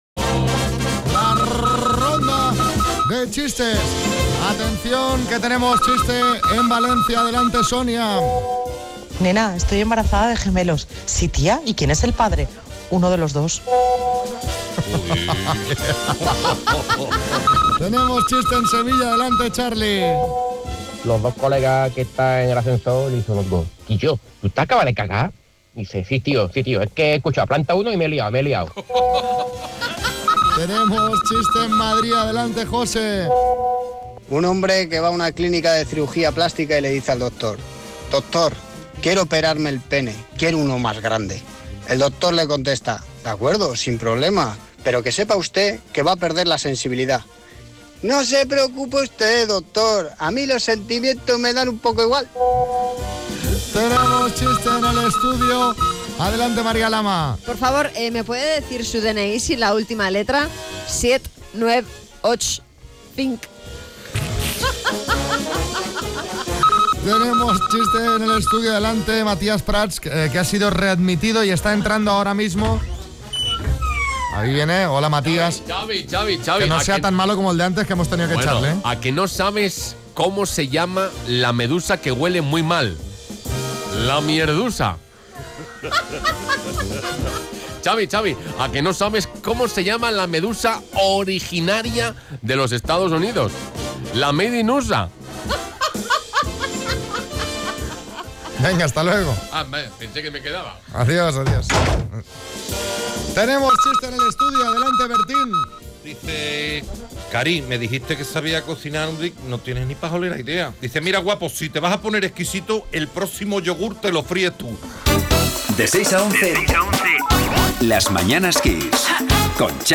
Nuestra ronda de chistes de hoy ha hecho para da en Sevilla, Madrid y Valencia.